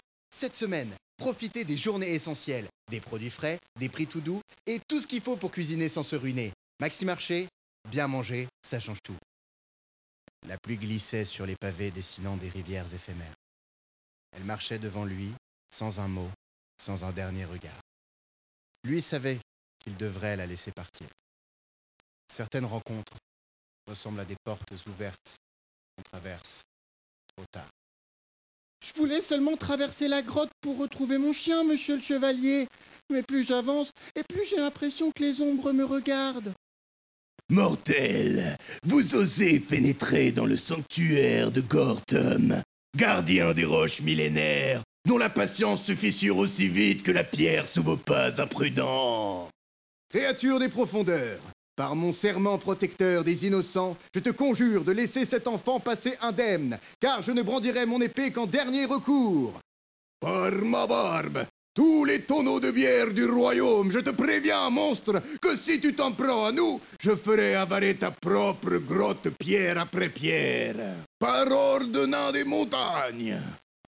bande démo voix acting